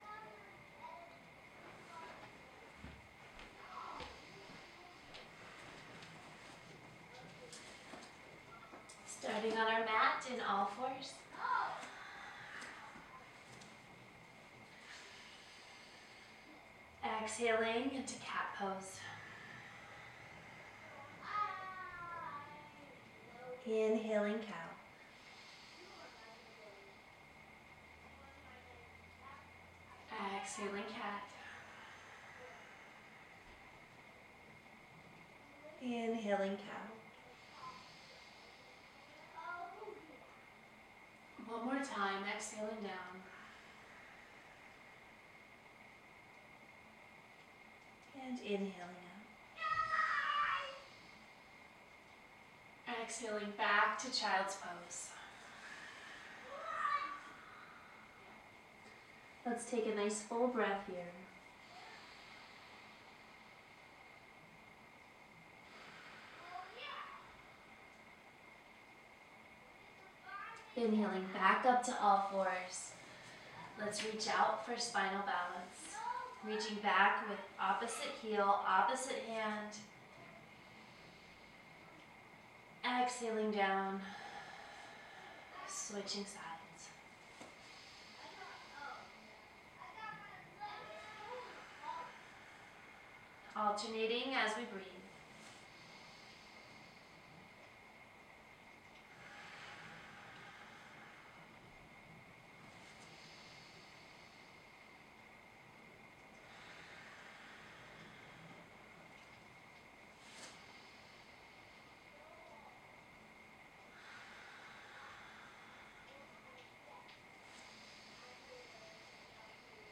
Once you are confident, you may prefer to follow along with the flow sequence in audio only to allow a deeper connection to your body, breath and spirit.